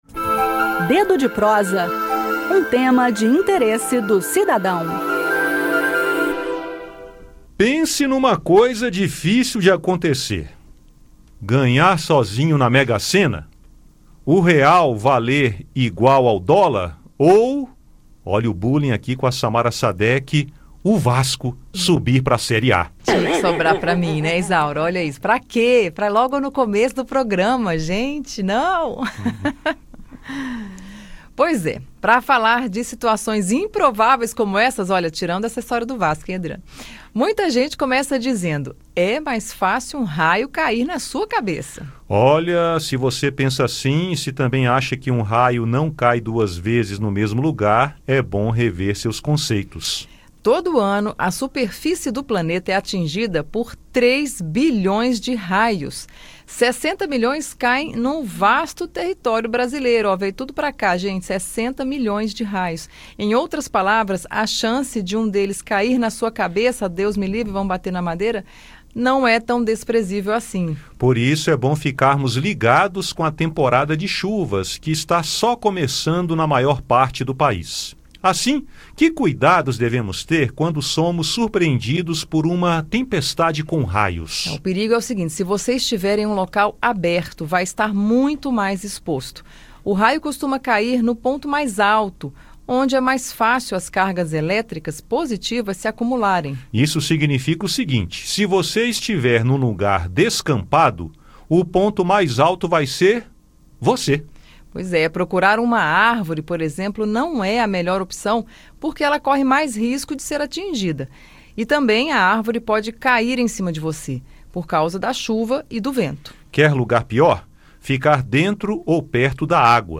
Com o início da temporada de chuvas, iniciam-se os alertas para evitar acidentes com raios. Ouça no bate-papo o que dizem os especialistas sobre cuidados e dicas para escolher um local seguro durante uma tempestade.